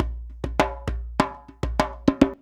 100DJEMB15.wav